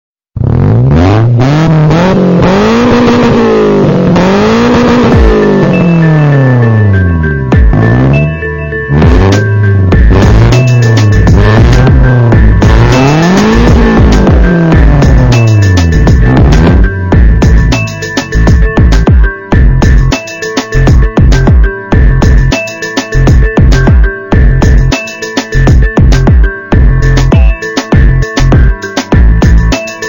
Som de Motor para Toque de Celular
som-de-motor-para-toque-de-celular-pt-www_tiengdong_com.mp3